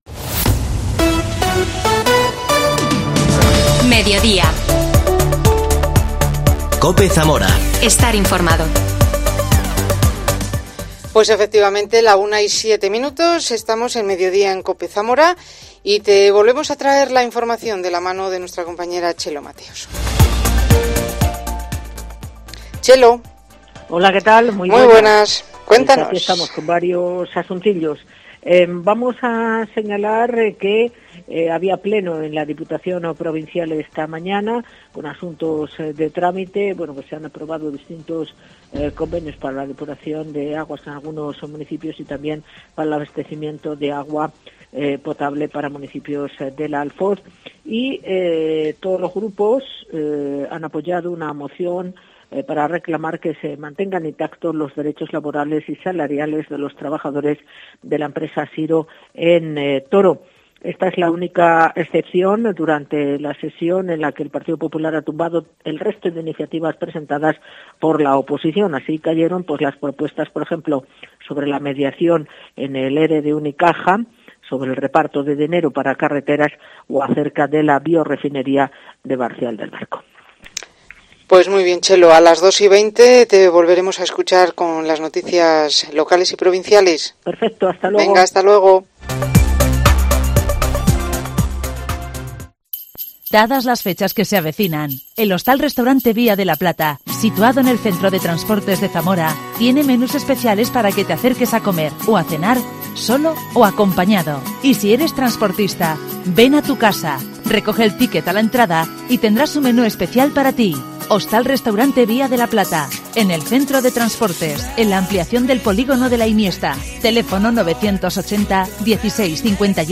AUDIO: Entrevista a la escritora